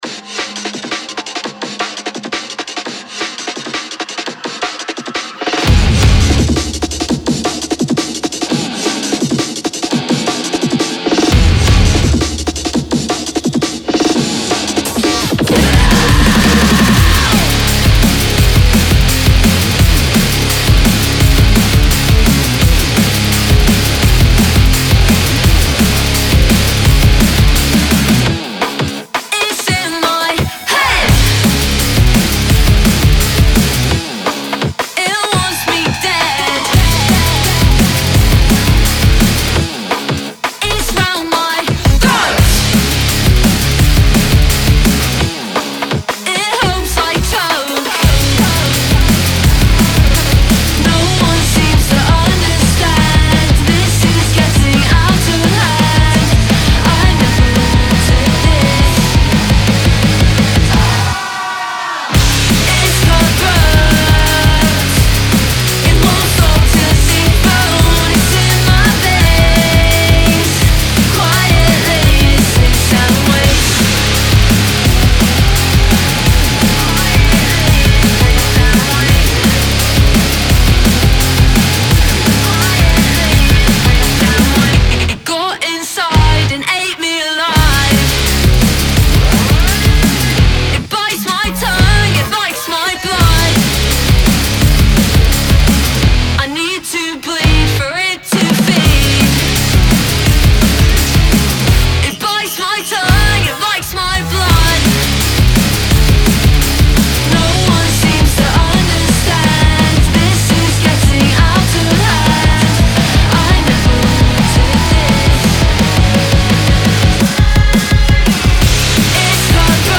BPM170-170
Audio QualityPerfect (High Quality)
Full Length Song (not arcade length cut)